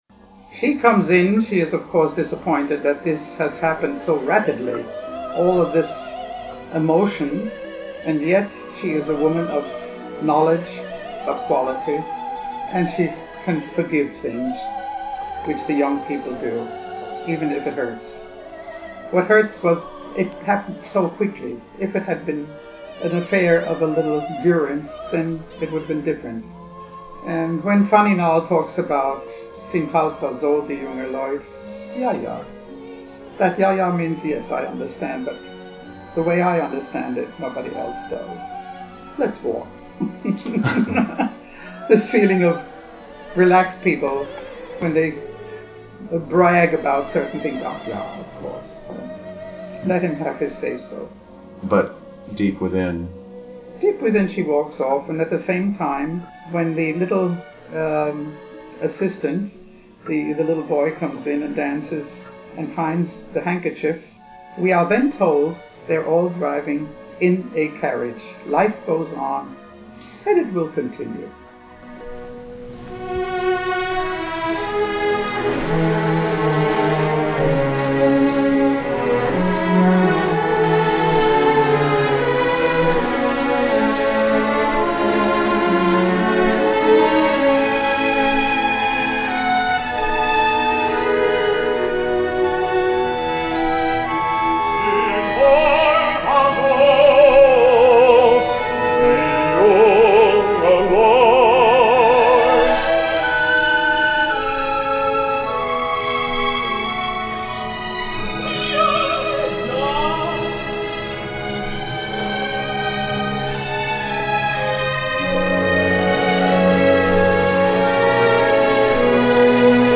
John Brownlee / Astrid Varnay
Fritz Reiner, conductor